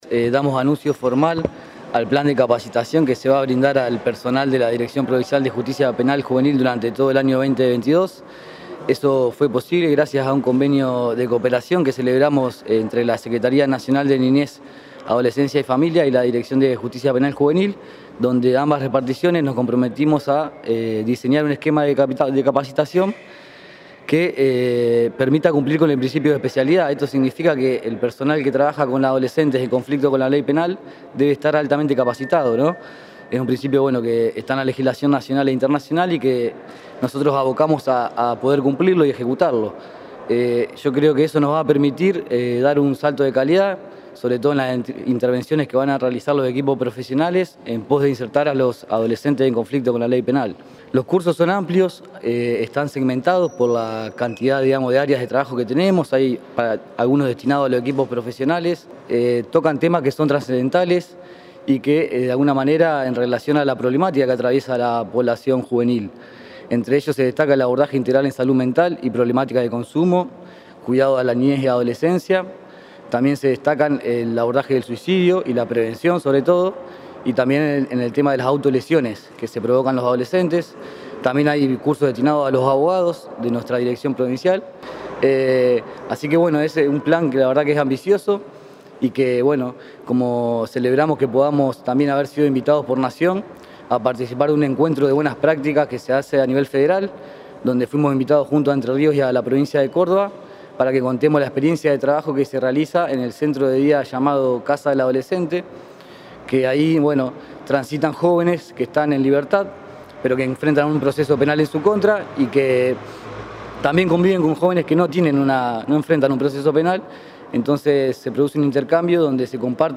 Federico Lombardi, director provincial de Justicia Penal Juvenil.
Declaraciones de Federico Lombardi